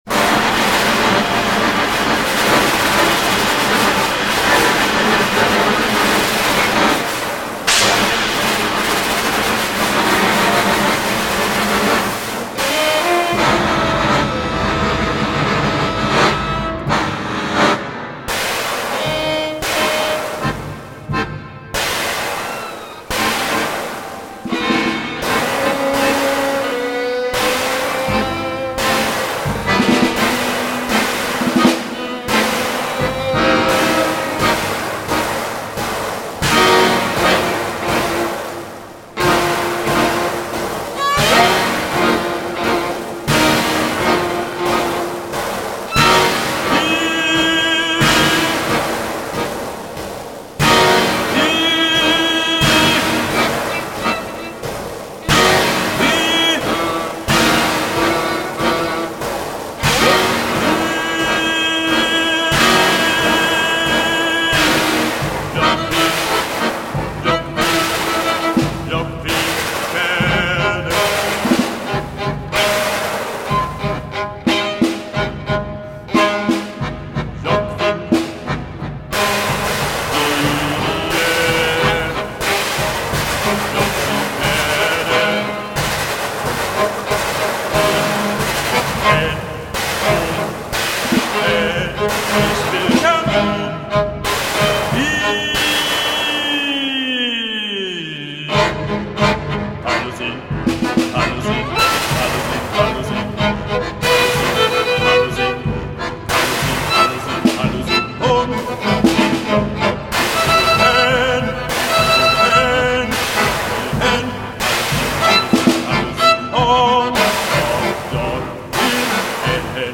finnish tangos